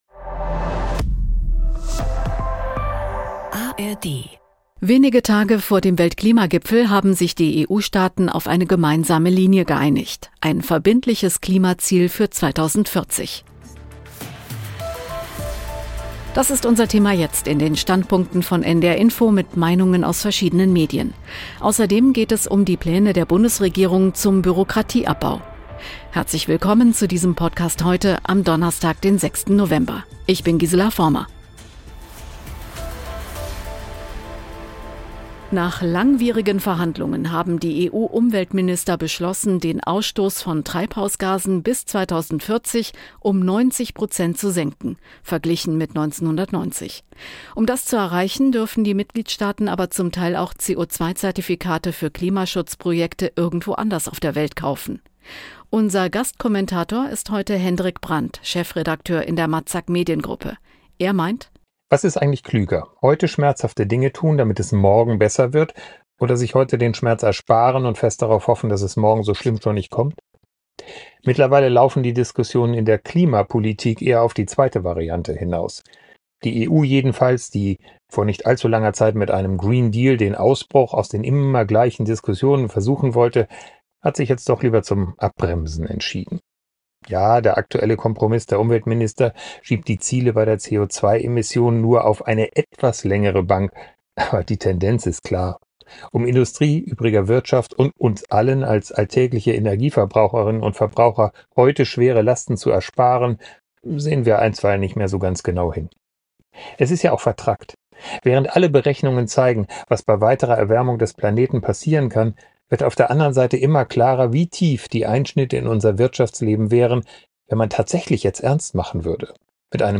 Kommentar